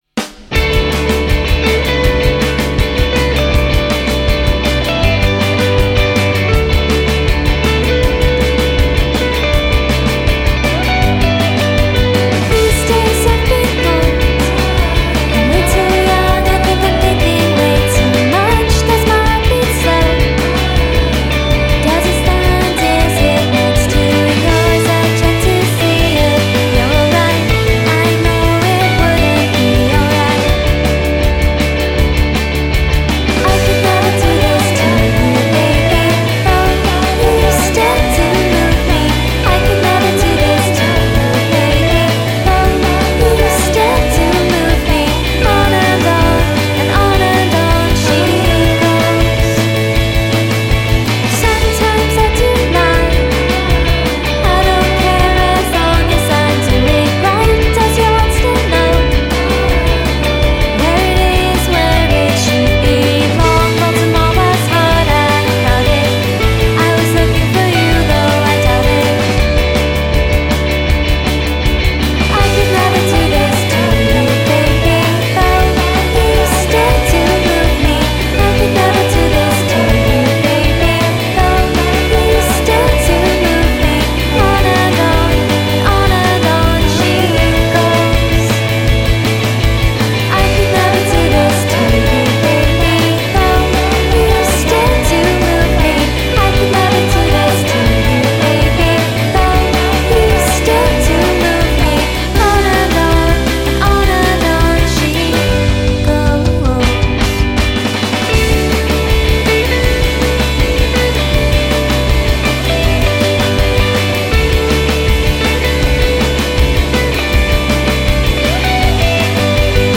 Dieci canzoni di indiepop scintillante
e armonie dolcissime
soul Sixties